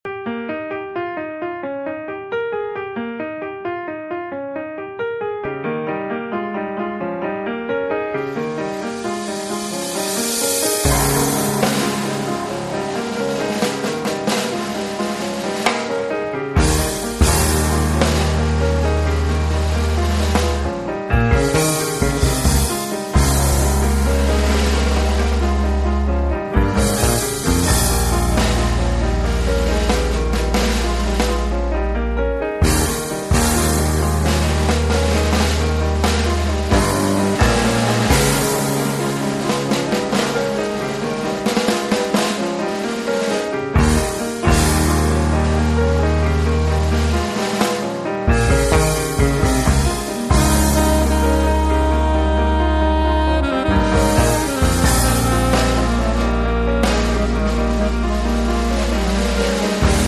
sax contralto
sax tenore
piano
contrabbasso e basso elettrico
batteria
una sorta di fusion acustica